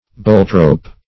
Search Result for " boltrope" : The Collaborative International Dictionary of English v.0.48: Boltrope \Bolt"rope`\, n. (Naut.) A rope stitched to the edges of a sail to strengthen the sail.